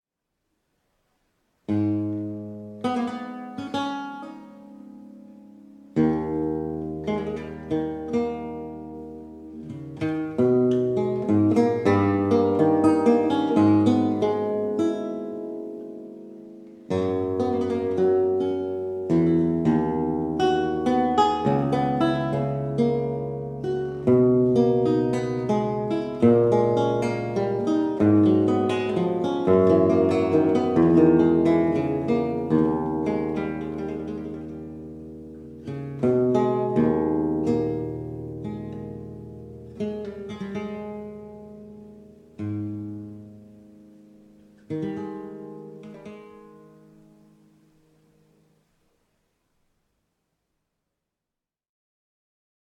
Lute
Houghton Memorial Chapel